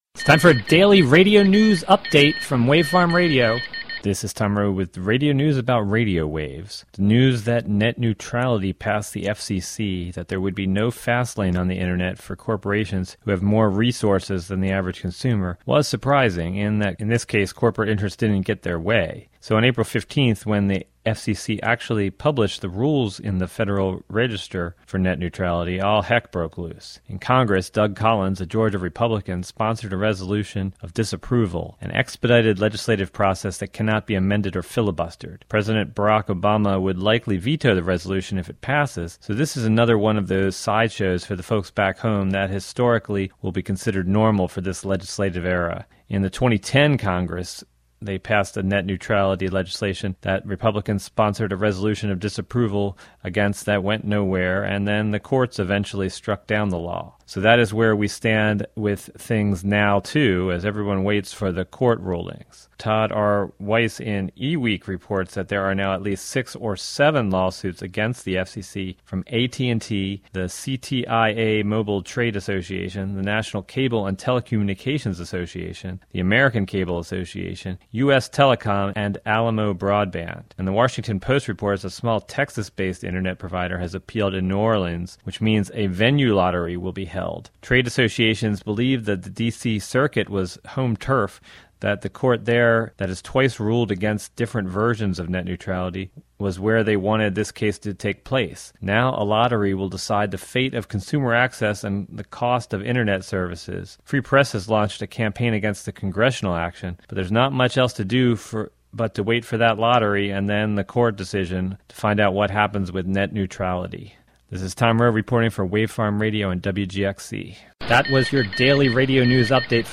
Report about net neutrality rules, now published, and now challenged in Cogress and the courts.